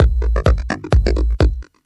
Electrohouse Loop 128 BPM (15).wav